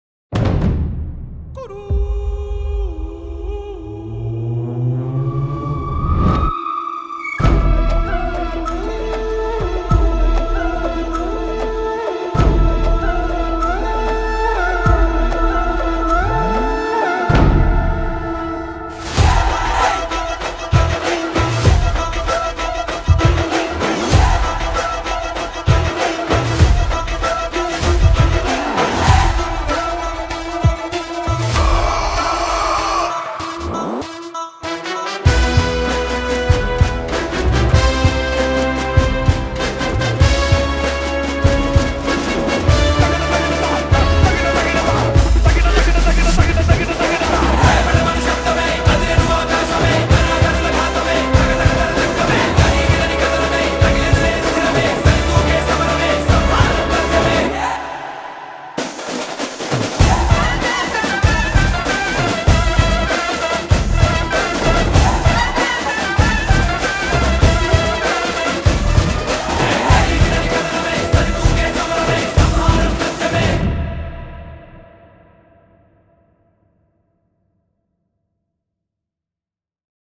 BGM
massive theme